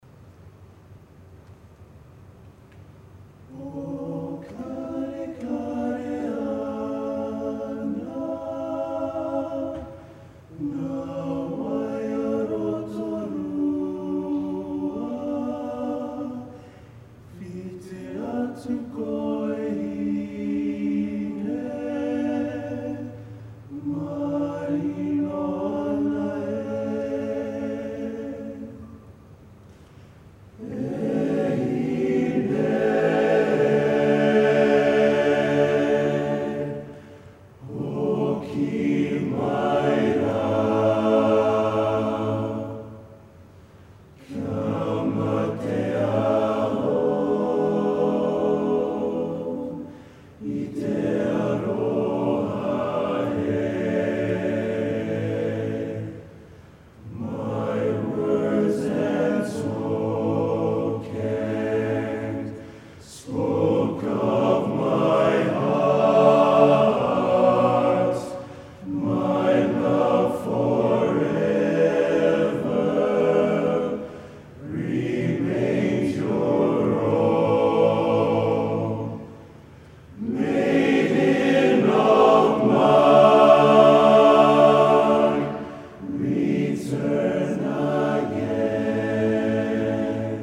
Recordings from The Big Sing National Final.